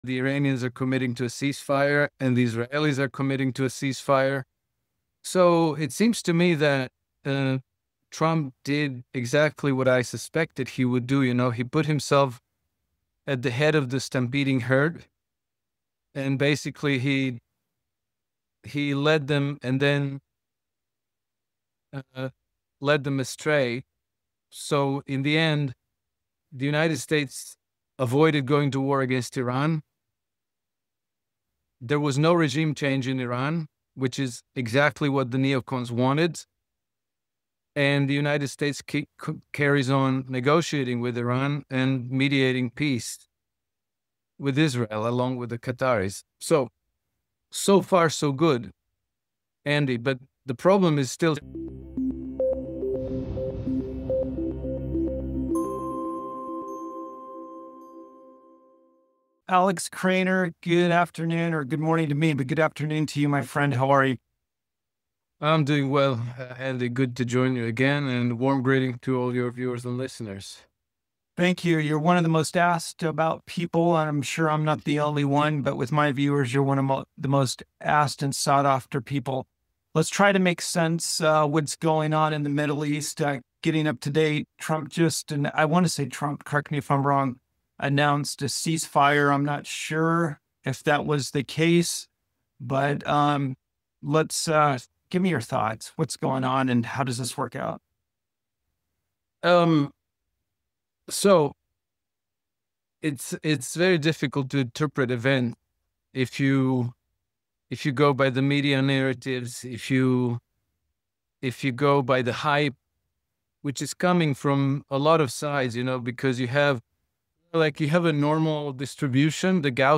In this in-depth discussion